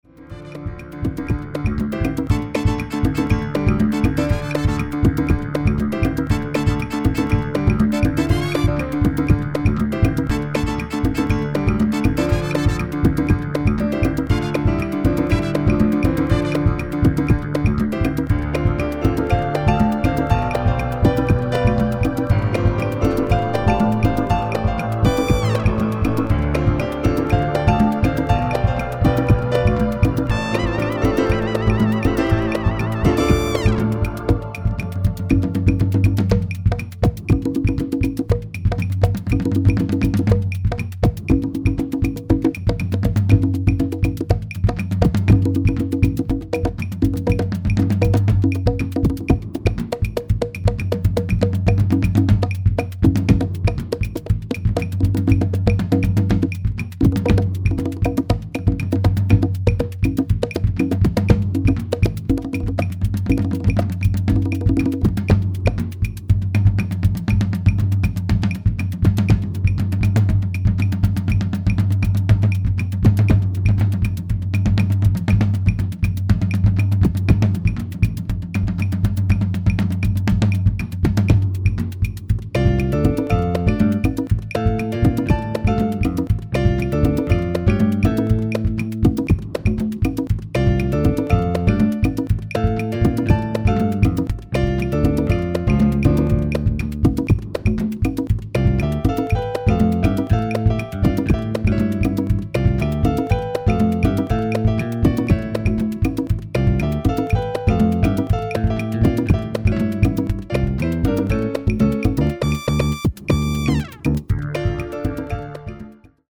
The music is rhythmic & percussion based.
Recorded using computer technology.
Cuban imfluences